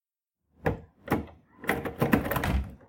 声音银行 " 进来玩吧 桌子和椅子
描述：触摸桌子和椅子
标签： 手机 现场 记录
声道立体声